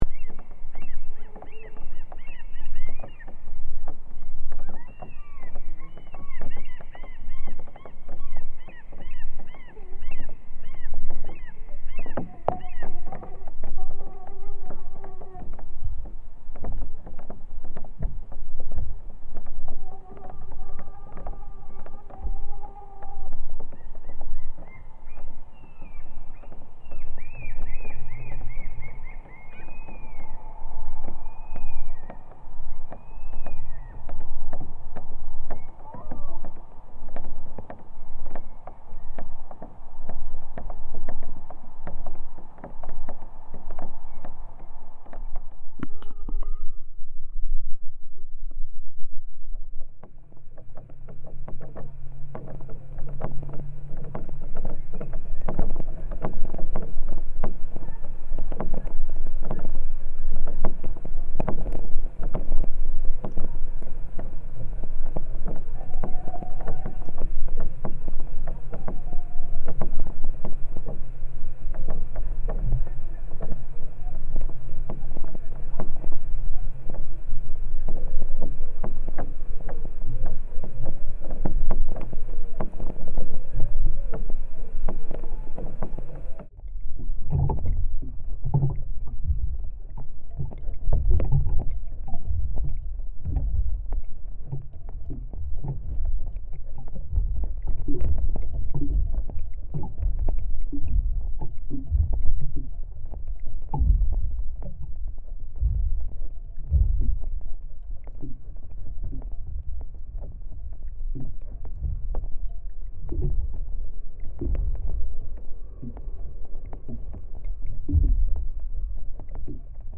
The recording is made sitting on a pier by the Kukkia lake. One can hear waves under the pier and sounds coming across the lake but also insects. The contact microphone was placed on a floating metal plate on water and animals smaller than the eye could see were walking on it and made sounds.